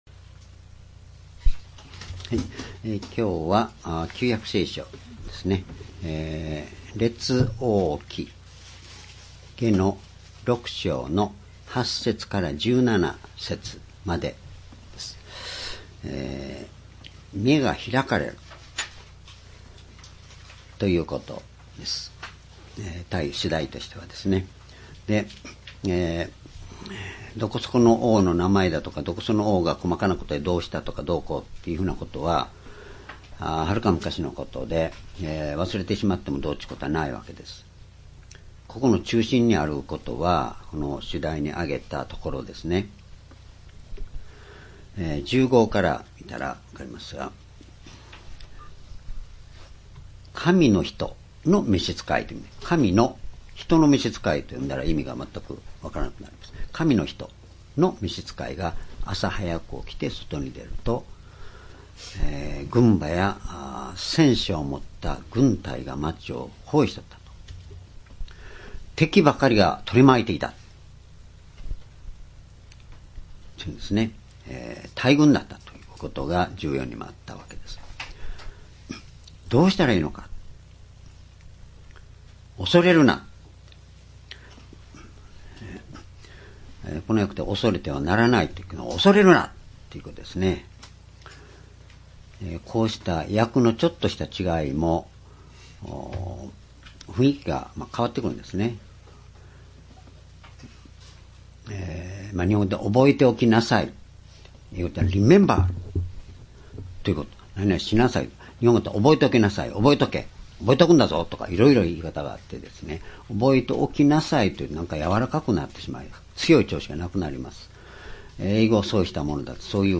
（主日・夕拝）礼拝日時 2019年3月3日 主日 聖書講話箇所 「目が開かれる」 列王記下6章8節～17節 ※視聴できない場合は をクリックしてください。